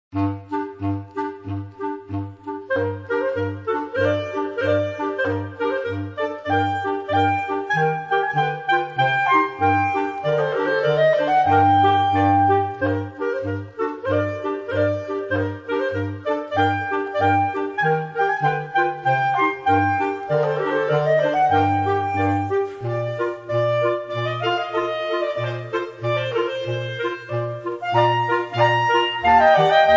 Gattung: Klarinettenquartett